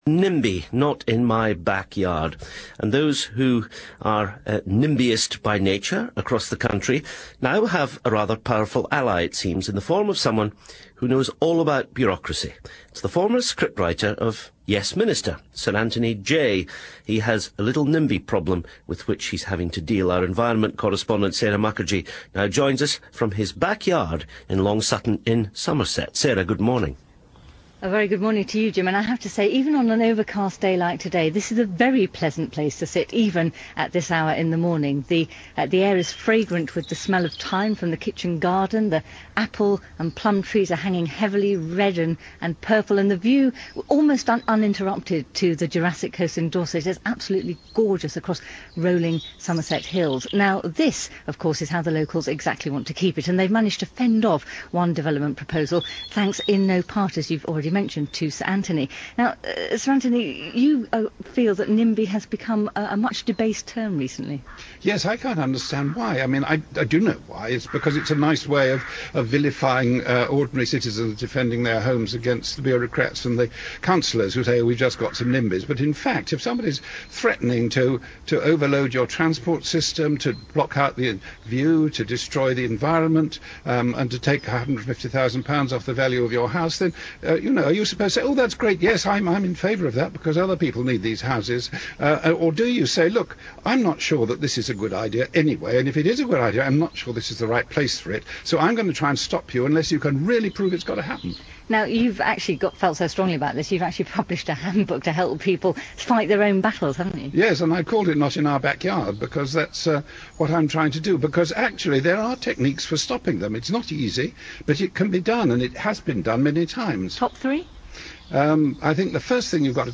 Listen to Antony Jay talk about his book - BBC 4 Today show 3.10.05